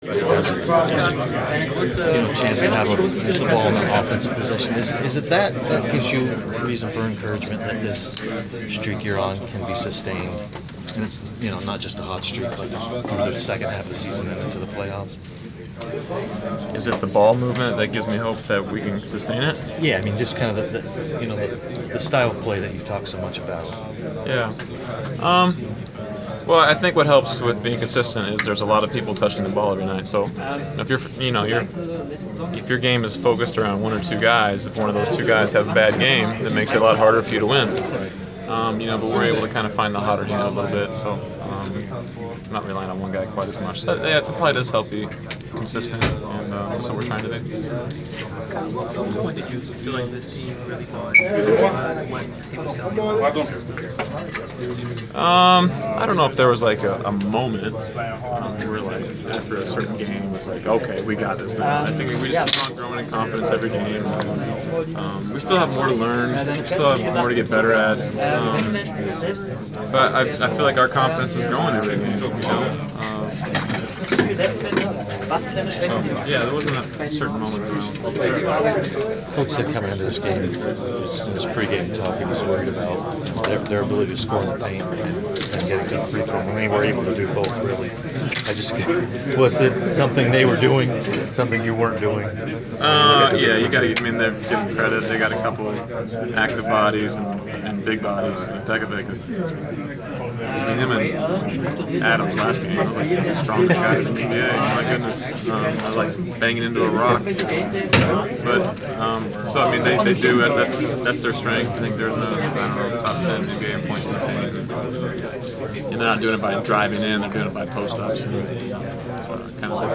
Inside the Inquirer: Postgame presser with Atlanta Hawks’ Kyle Korver (1/25/15)
We attended the postgame presser of Atlanta Hawks’ guard Kyle Korver following his team’s 112-100 home win over the Minnesota Timberwolves on Jan. 25.